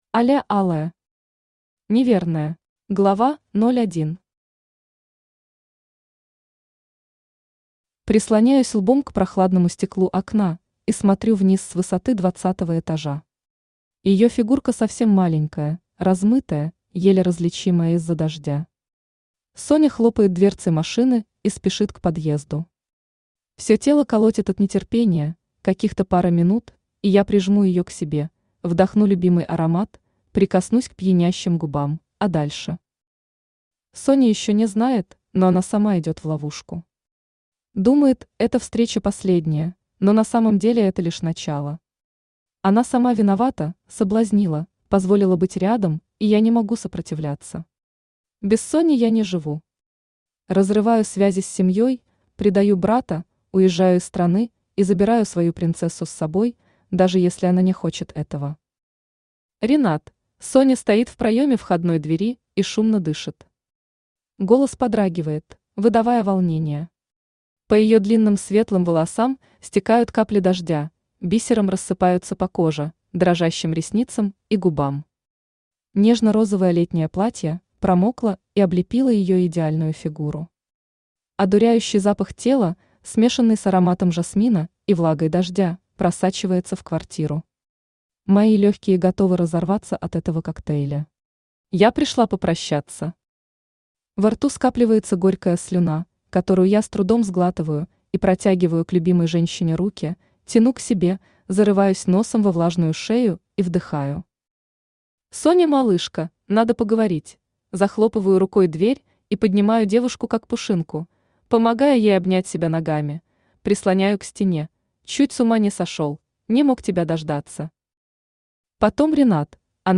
Aудиокнига Неверная Автор Аля Алая Читает аудиокнигу Авточтец ЛитРес. Прослушать и бесплатно скачать фрагмент аудиокниги